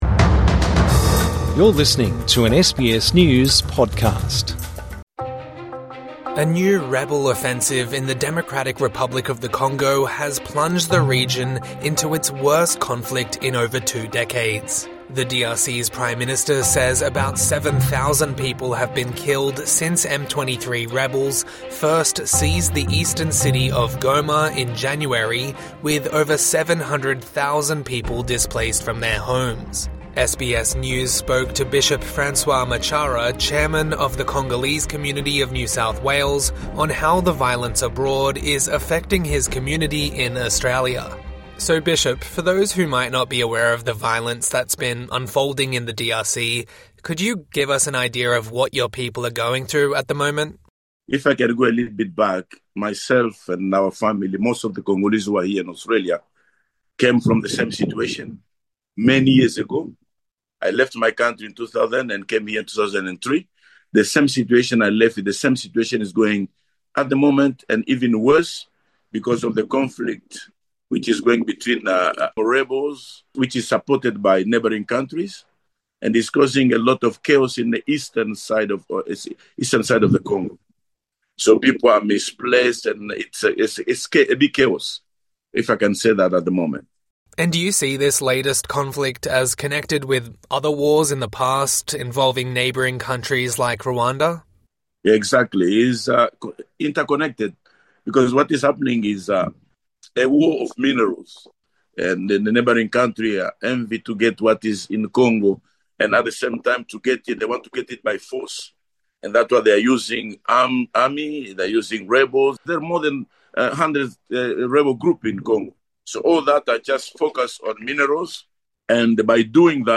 INTERVIEW: How is Australia's Congolese community dealing with war in their homeland?